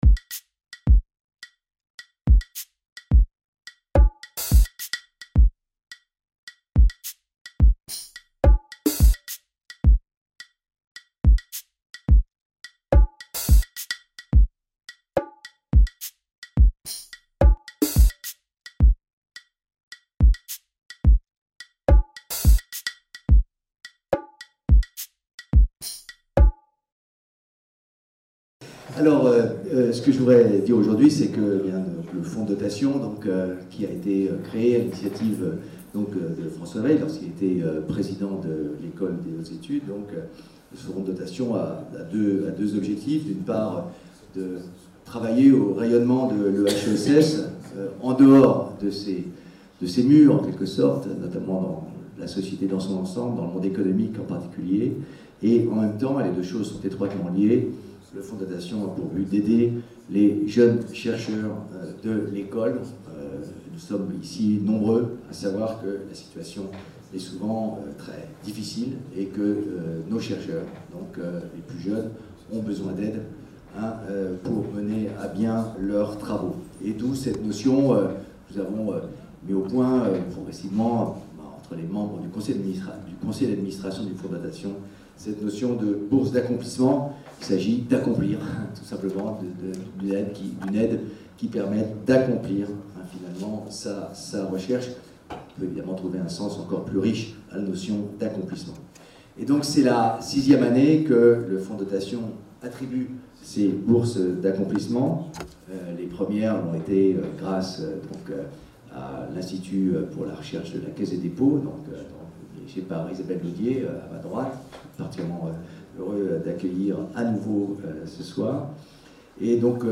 Une table ronde s'est déroulée autour du thème « Sport et recherche ».